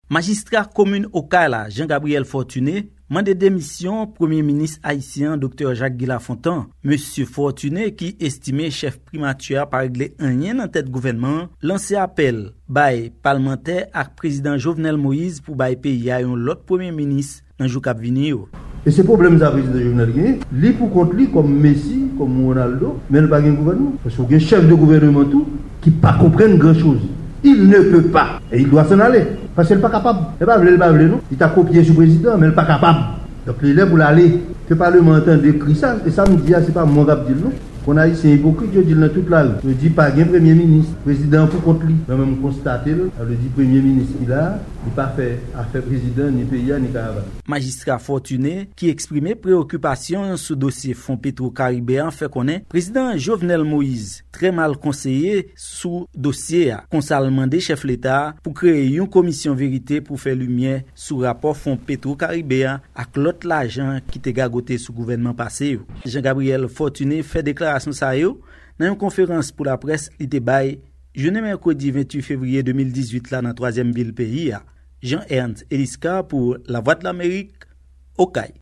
Deklasyon Majistra Okay la, Jean Gabriel Forntuné